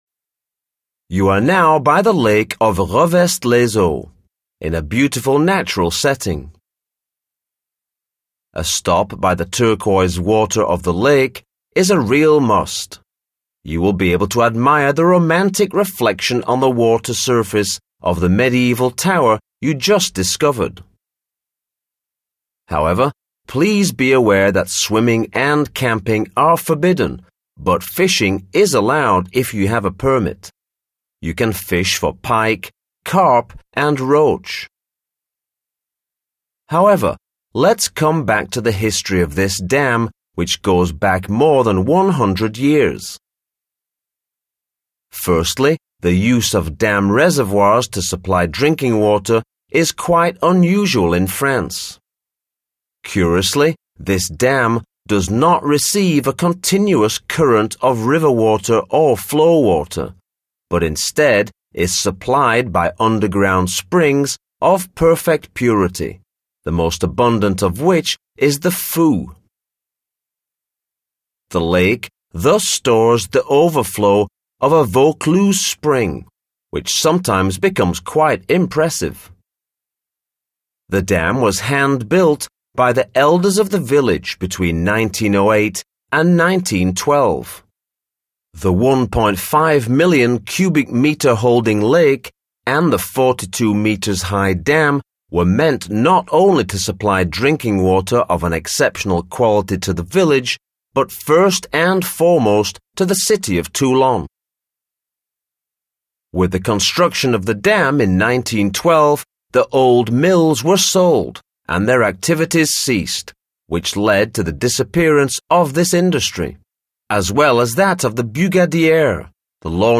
Audioguide version anglaise Les Revest-les-Eaux